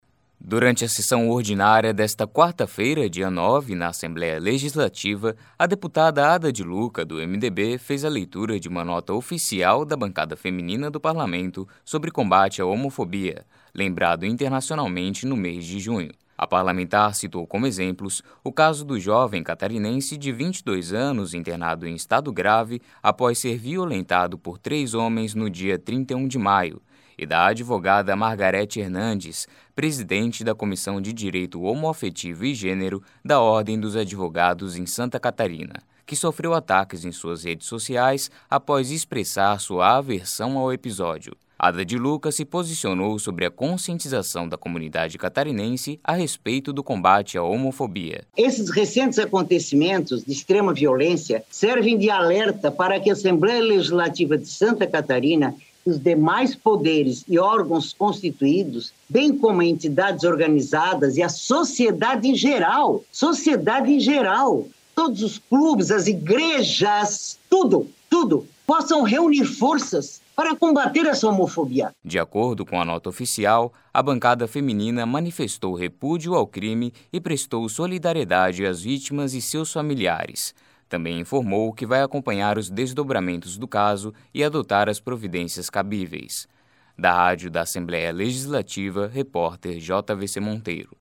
Durante a sessão ordinária da última quarta-feira (9) na Assembleia Legislativa, a deputada Ada de Luca (MDB) fez a leitura de uma nota oficial da Bancada Feminina do Parlamento sobre o combate à homofobia, lembrado internacionalmente no mês de junho.
Entrevista com:
- deputada Ada de Luca (MDB).